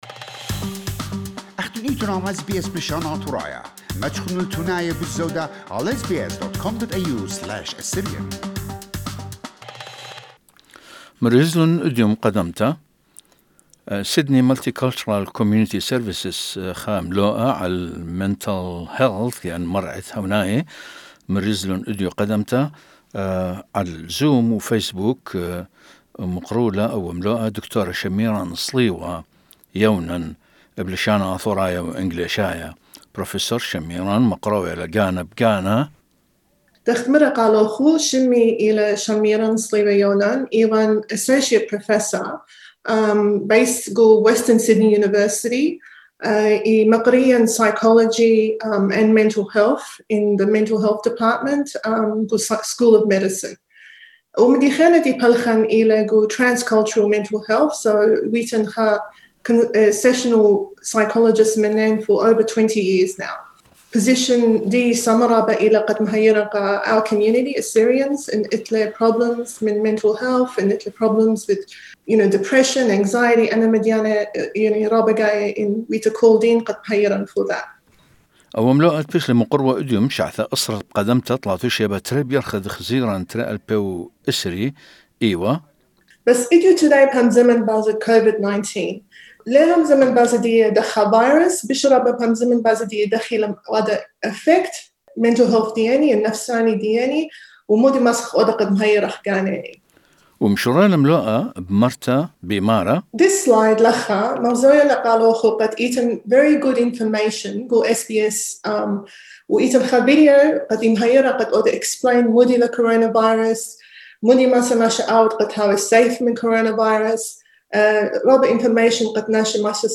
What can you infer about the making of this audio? The lecture was organized by Sydney Multicultural Community Services, it was presented live on Facebook and via Zoom, Tuesday 2 June at 10 AM. We selected some main points from the presentation.